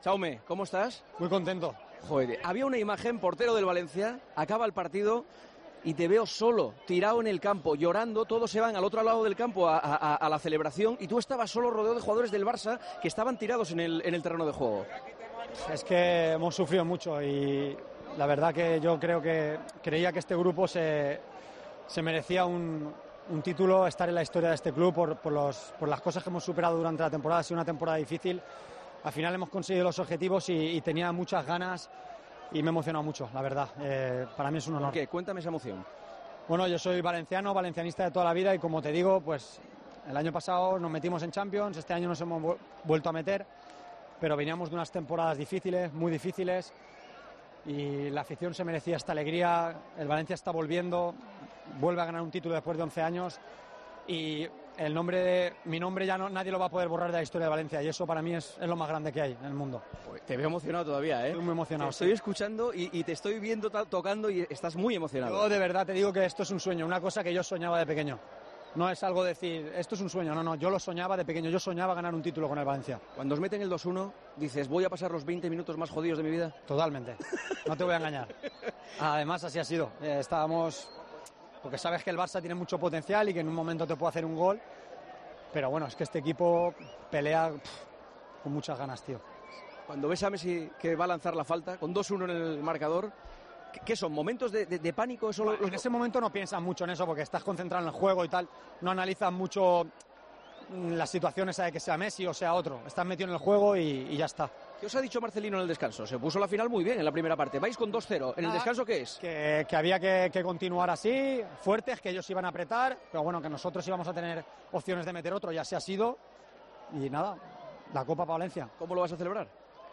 Entrevistamos al portero del Valencia tras ganar su primer título con el equipo che